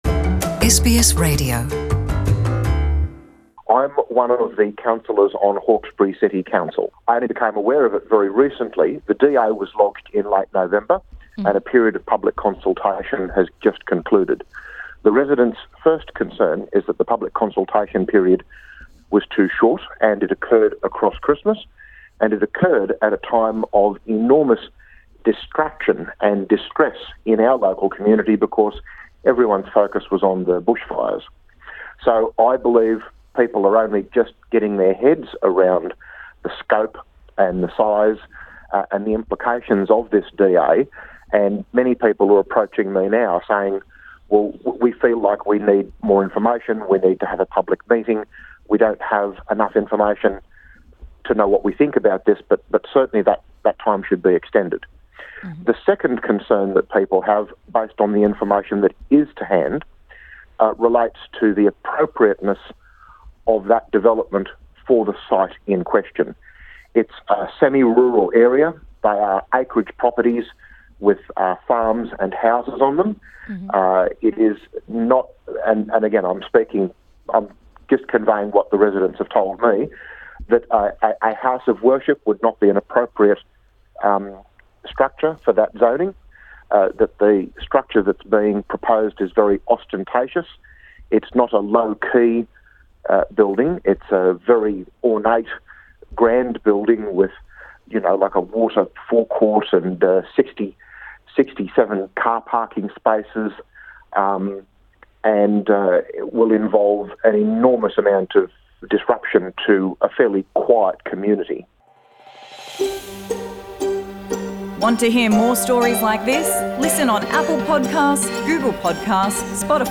Hawkesbury City councillor, Nathan Zamprogno Source: Facebook